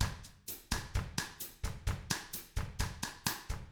129BOSSAF1-L.wav